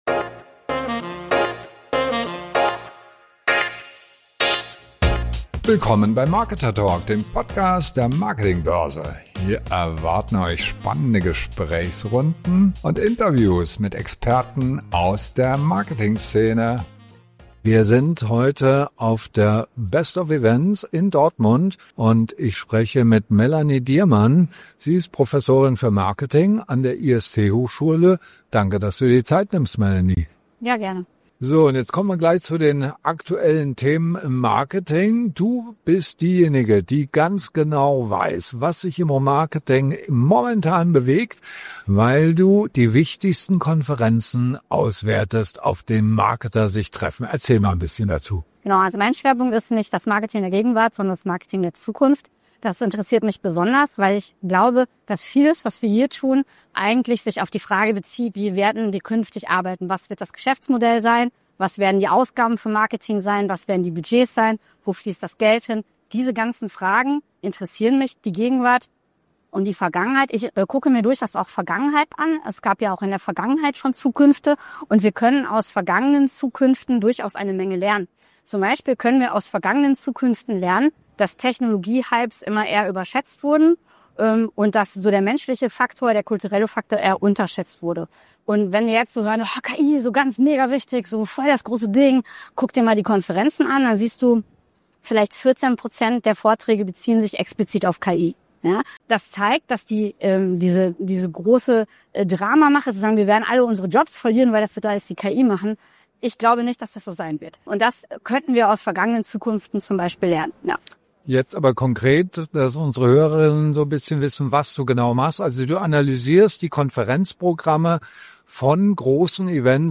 KI wird überall gehypt, doch auf den großen Marketing-Konferenzen sprechen nur 14% der Redner explizit davon. Was bedeutet das für die Zukunft deines Marketings? Ein Gespräch über Agenten, Daten und die blindspots, die wir übersehen.